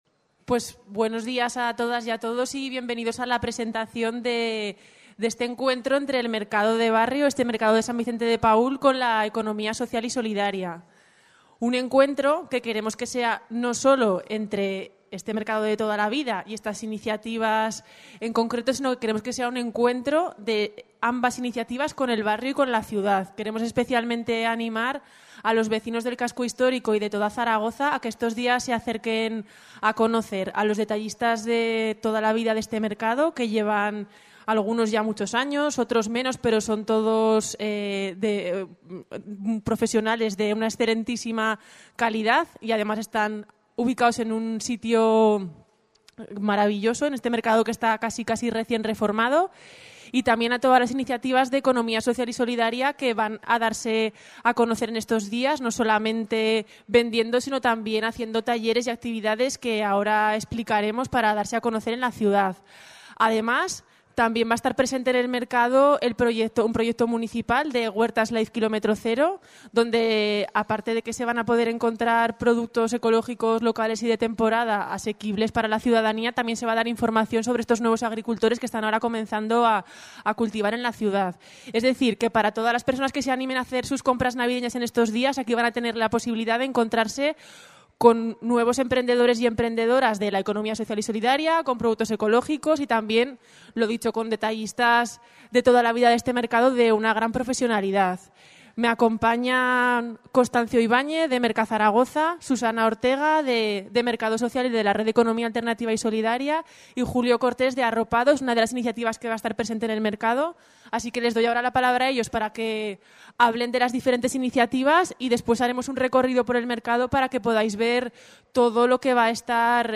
Escuche aquí las declaraciones de la concejala delegada de Medio Ambiente y Movilidad, y presidenta de la Junta de Distrito del Casco Histórico, Teresa Artigas: Más información Agenda Zaragoza Documentos Adjuntos 151217teresaartigasenenclaveverdesanvicentedepaul.mp3